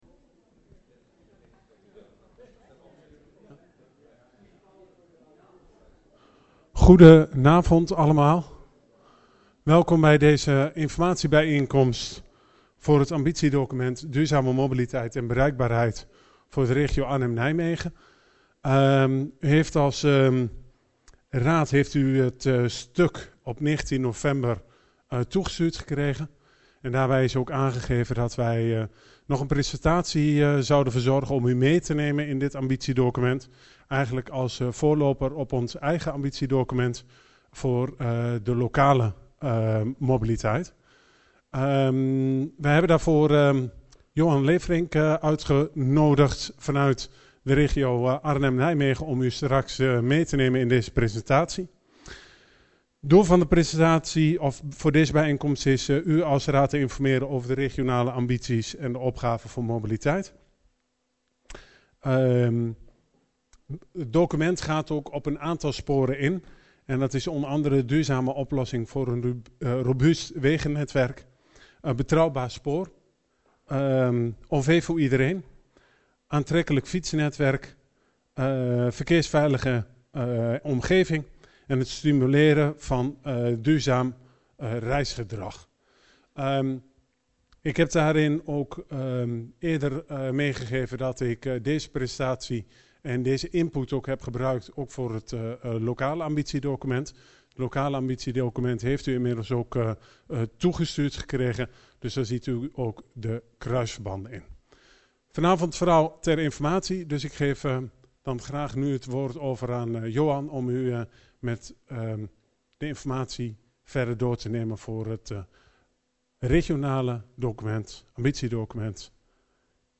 Locatie VMBO Het Westeraam Elst Toelichting Informatiebijeenkomst college Toelichting ambitiedocument duurzame mobiliteit en bereikbaarheid regio AN Agenda documenten 20-02-04 Opname 1.